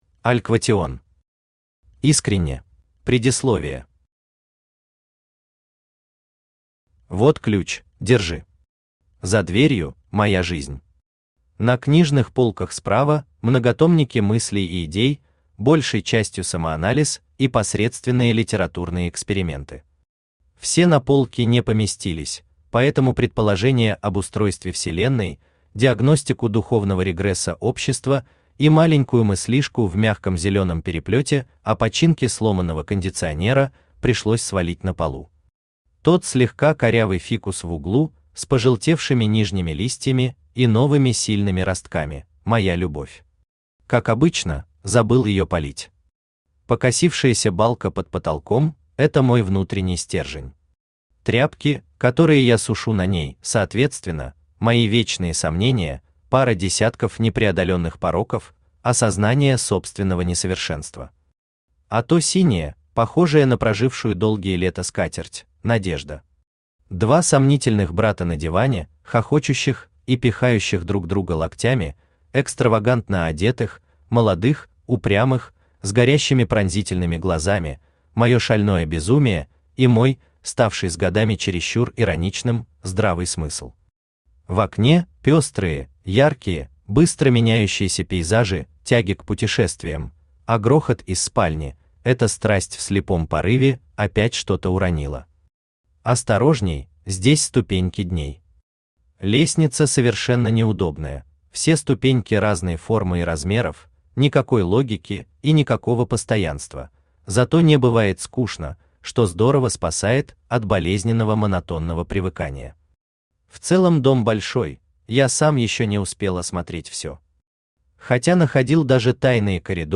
Aудиокнига Искренне Автор Аль Квотион Читает аудиокнигу Авточтец ЛитРес.